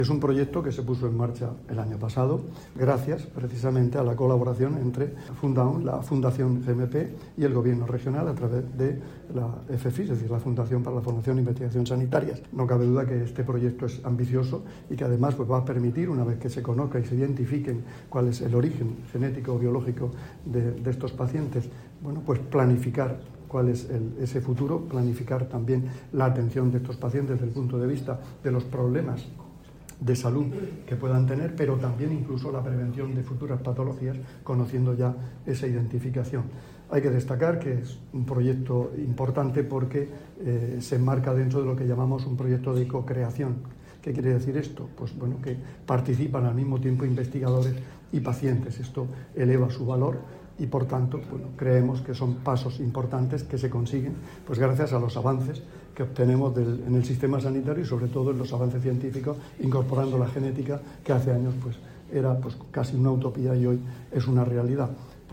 Declaraciones del consejero de Salud, Juan José Pedreño, sobre el proyecto de investigación 'De la medicina genómica a la prevención personalizada en adultos con discapacidad intelectual. Hacia un envejecimiento activo y saludable'.